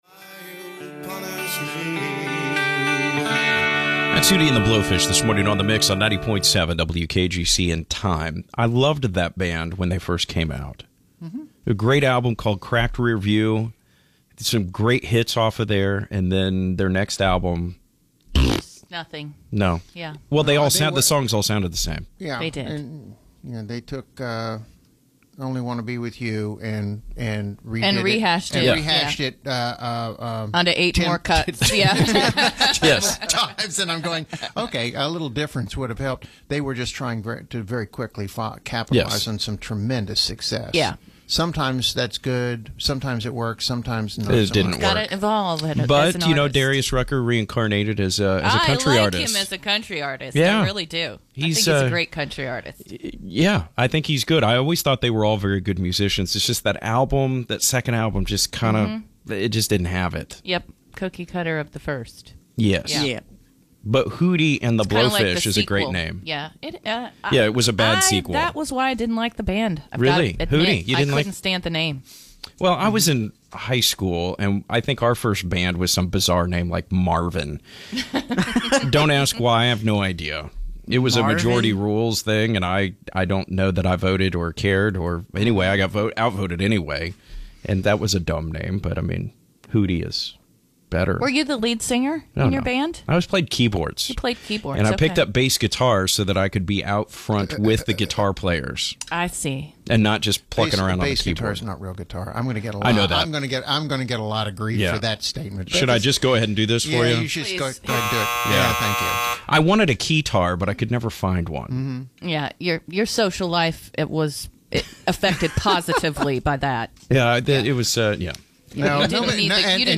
The crew is back in Studio for The Morning Mix! Together they talked history, music news, and more.